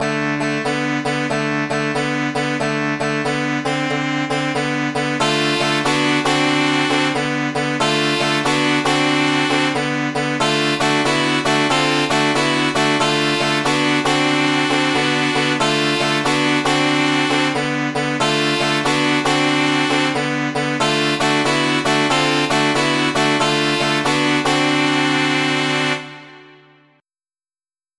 channel 8 is patch 66 pan 35, which is a tenor sax
or on a channel with a saxophone patch and a one-millisecond delay, for a doubling effect, with